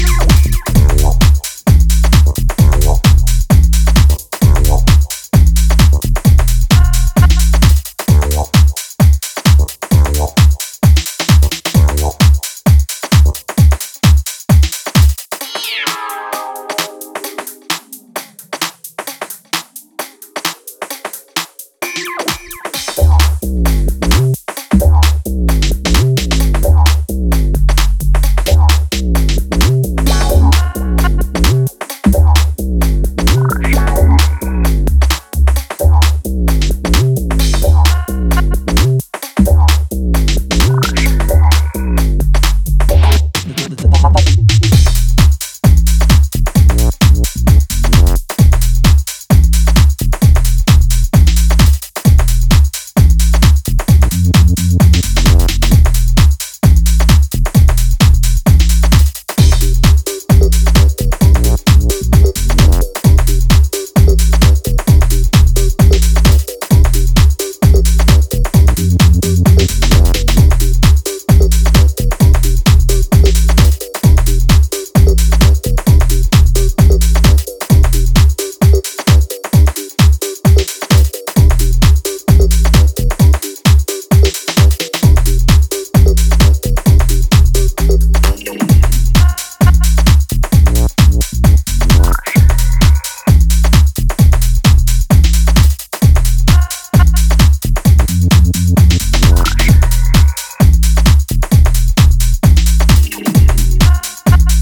a mescaline-strength take on classic ‘90s tech house
swinging, jacking 4 AM NRG